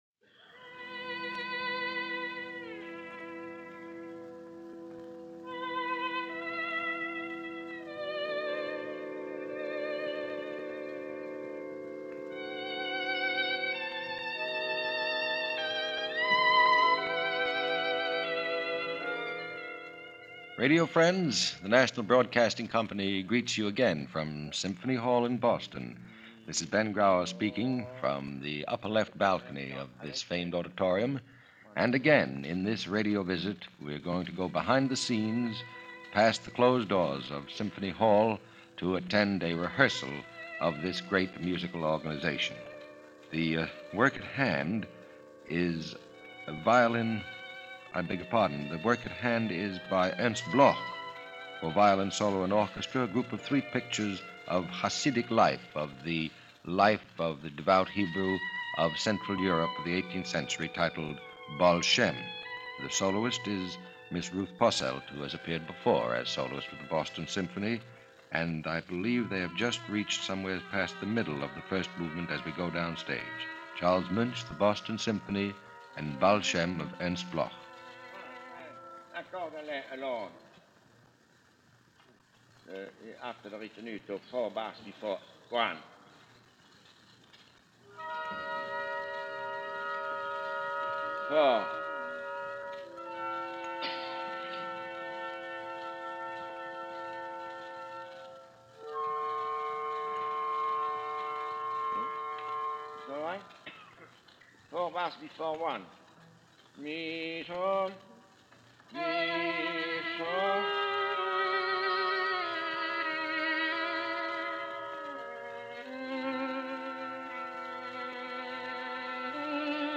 Ruth Posselt with Charles Munch and the Boston Symphony In Rehearsal – March 24, 1951
This week it’s the BSO under Music Director Charles Munch and the legendary American violinist Ruth Posselt in a rehearsal of Ernst Bloch‘s Baal Shem (Pictures of Hassidic Life in 3 Movements).